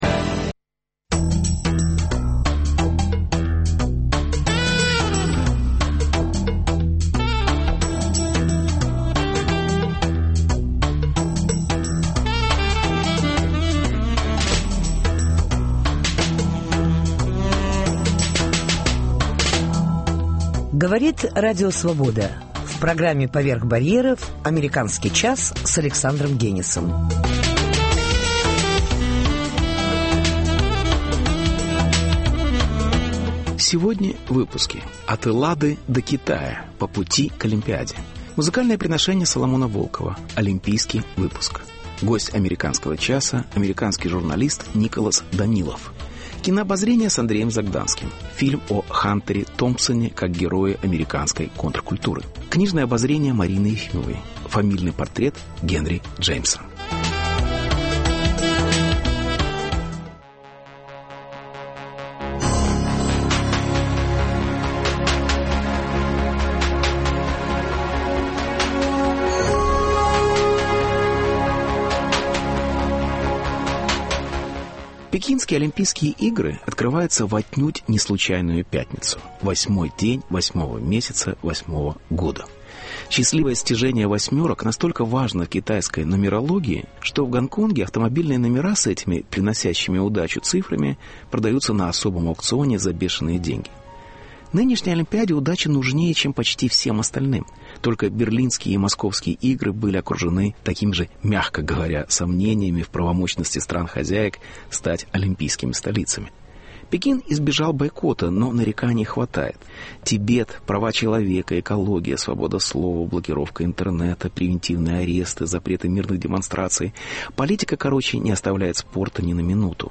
Беседа. От Эллады до Китая: по пути к Олимпиаде.